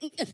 pegada2.ogg